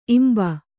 ♪ imba